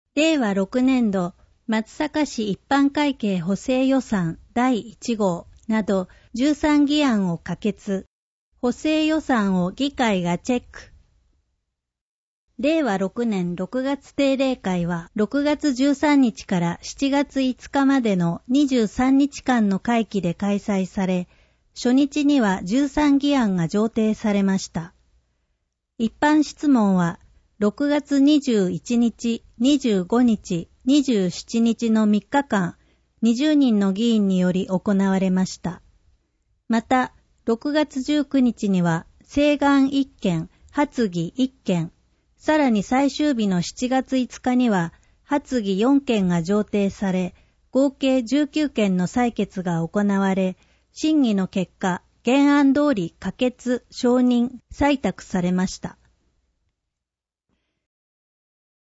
なお、この音声は「音訳グループまつさか＜外部リンク＞」の皆さんの協力で作成しています。